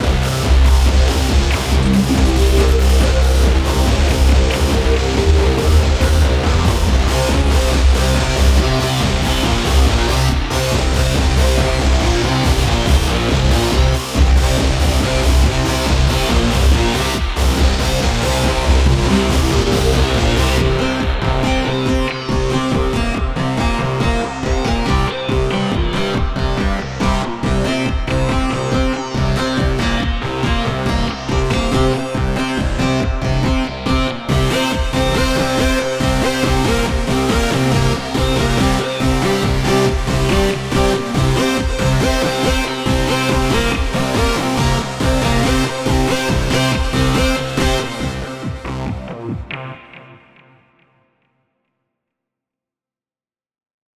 A short hardrock track